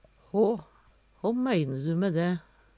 DIALEKTORD PÅ NORMERT NORSK hå kva Kven Kva Korleis Kvifor Kor håkken hå håss håffør hå, hårr Eksempel på bruk Hå meine du om dæ? Hør på dette ordet Ordklasse: Pronomen (spørjepronomen) Attende til søk